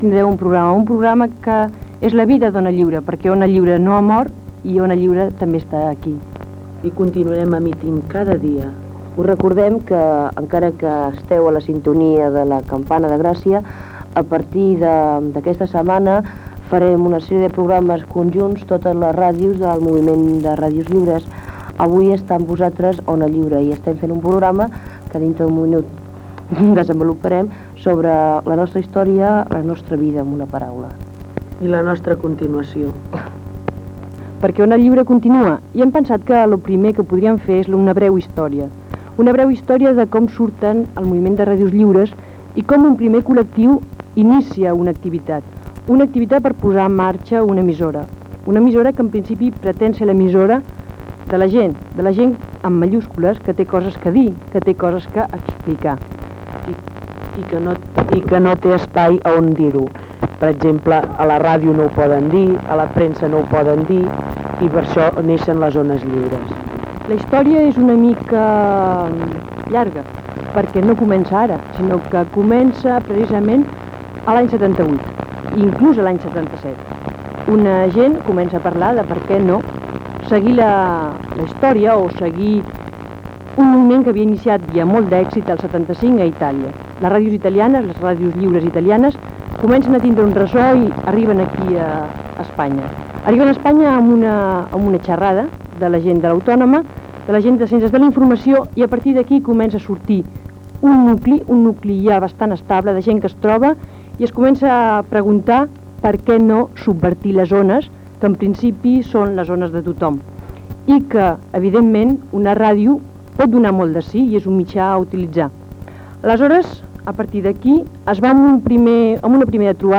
Informatiu
Emissió feta des de La Campana de Gràcia, dos dies després del quart tancament de l'emissora i precintatge de la seva seu i equips.